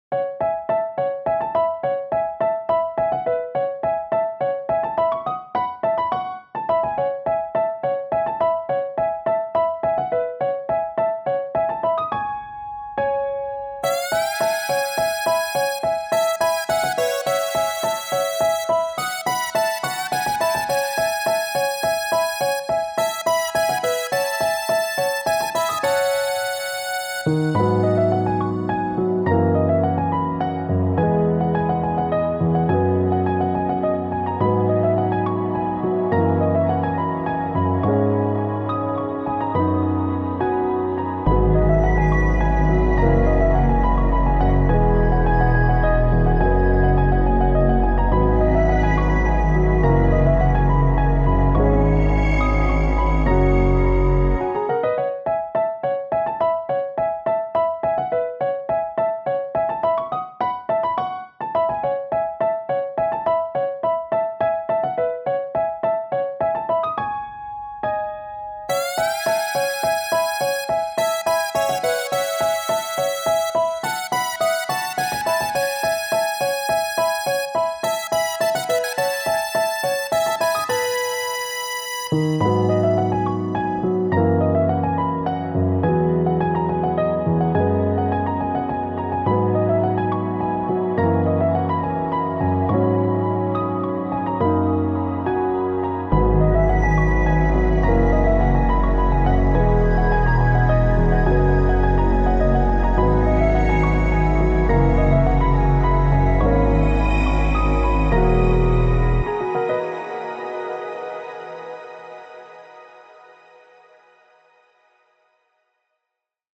Ambient / Classical